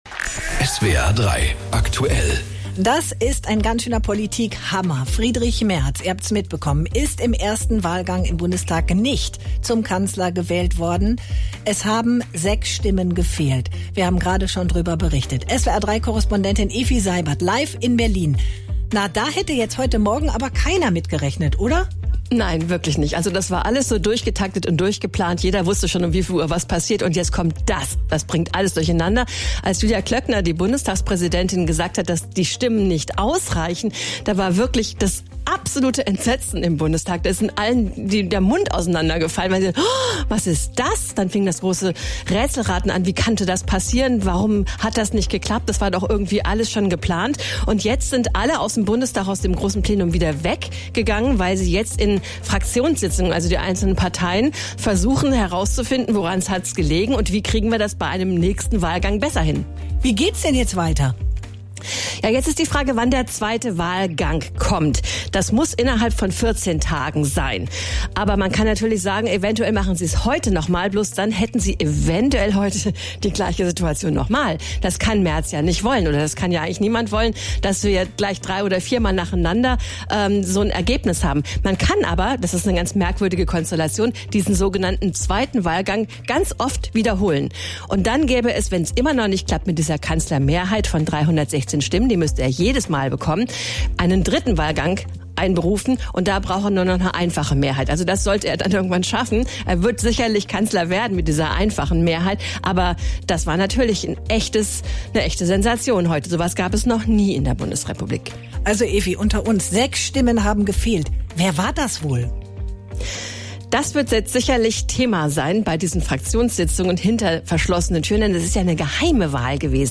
berichtet in Berlin